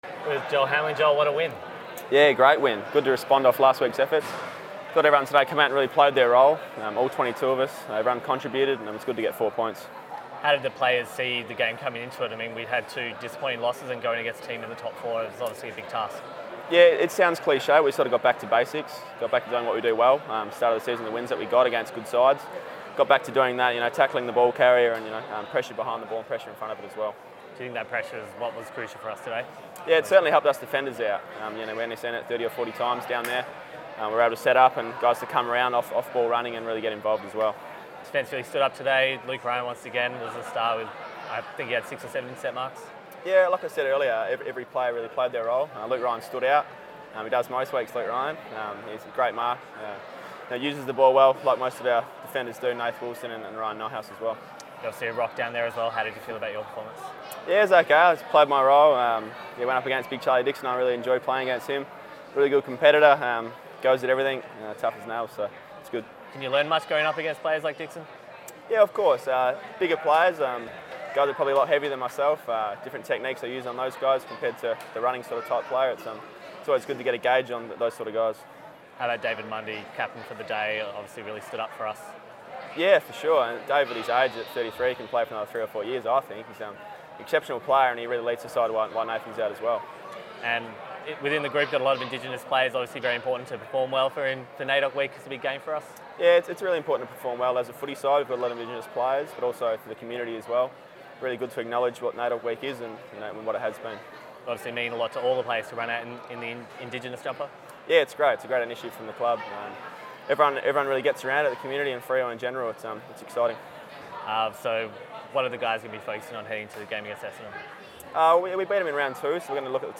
Joel Hamling chats to Docker TV after the round 17 win over Port Adelaide